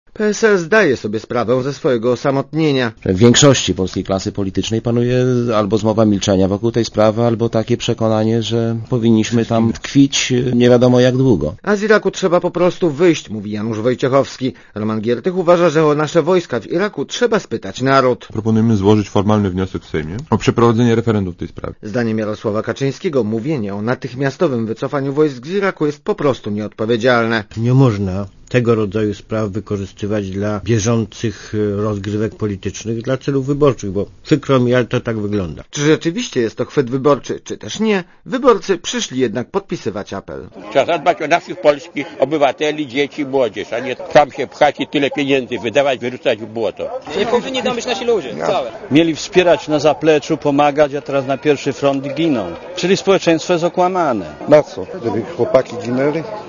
Do swojego pomysłu ludowcy namawiali warszawiaków spacerujących na Placu Zamkowym. Wzbudził on jednak burzliwą dyskusję wśród przechodniów.